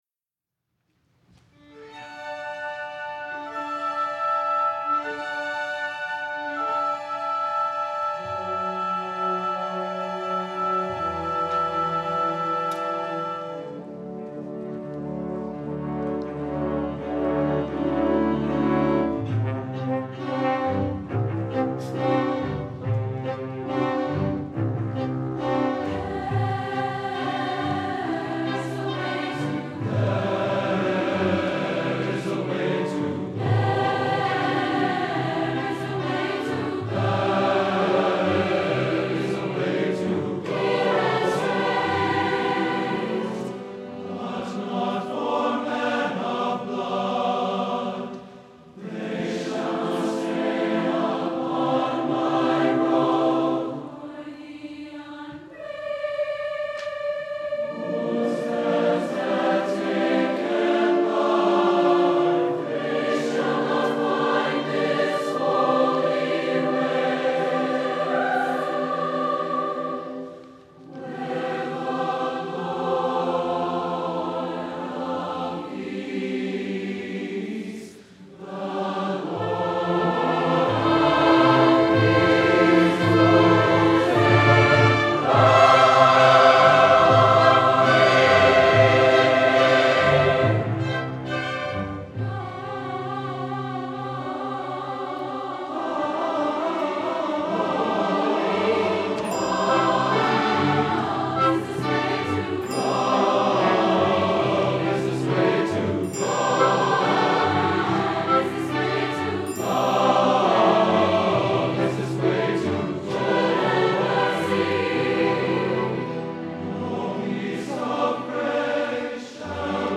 for SATB Chorus and Chamber Orchestra (2004-07)
There is a Way to Glory is a triumphant march to glory, as expressed by the lyrics:
The music is placed in a 2/2 marching meter, and the oscillating pitches on the word "glory" intend to reflect a trembling with ecstasy on the journey to heaven.